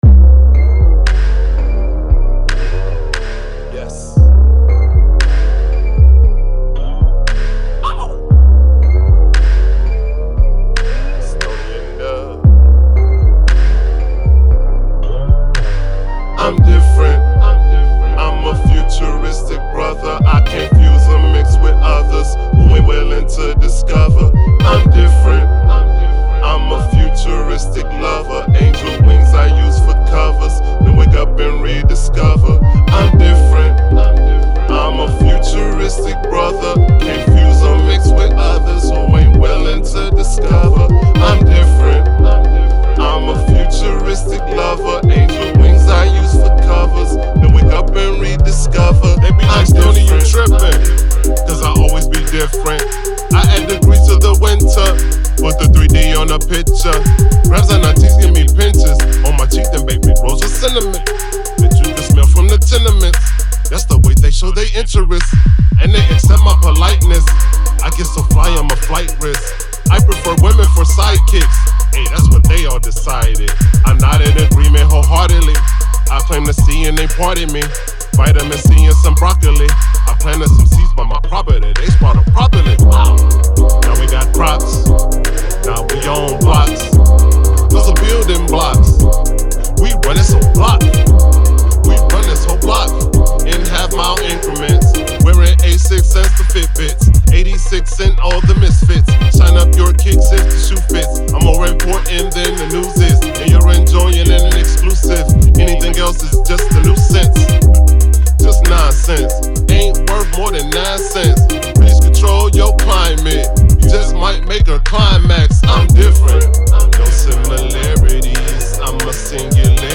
Hiphop
Laid-back single